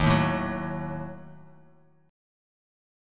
D#dim chord